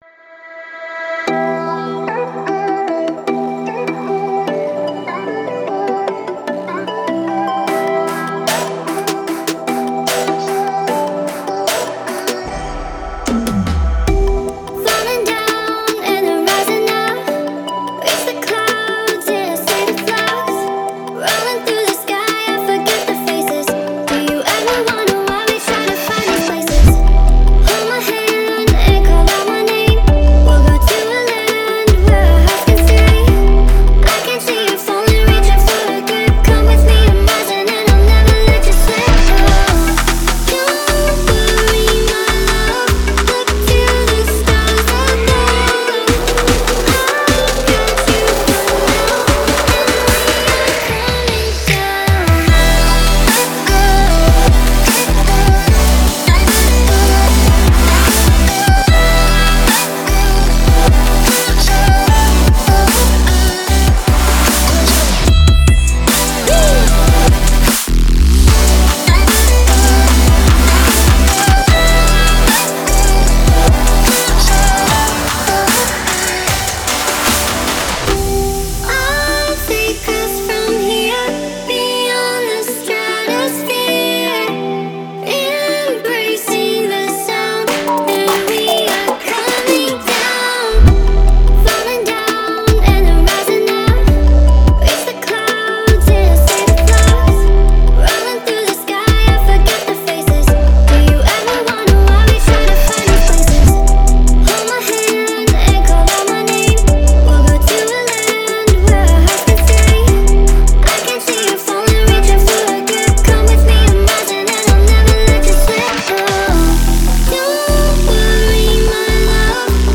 искренний и эмоциональный